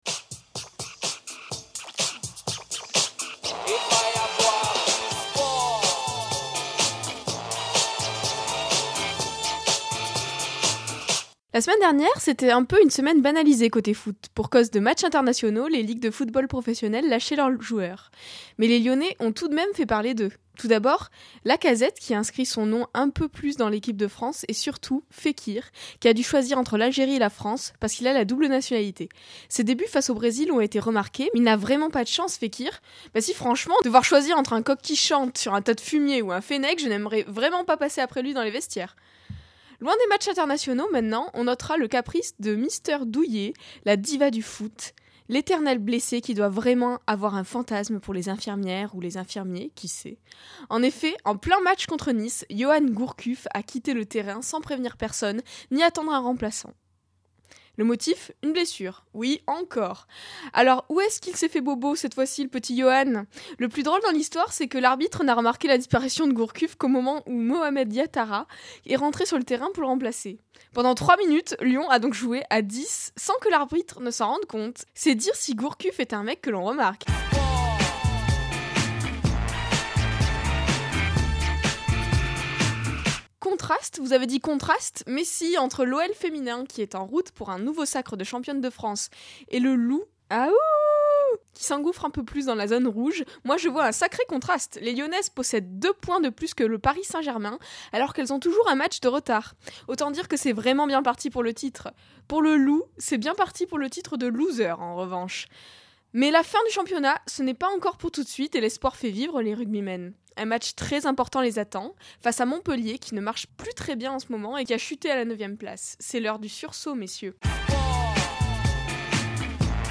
Attention, ça déménage !
Sport.mp3